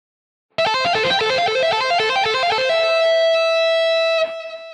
Гитарное упражнение 4
Аудио (100 УВМ)